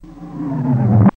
Drum Effects Reversed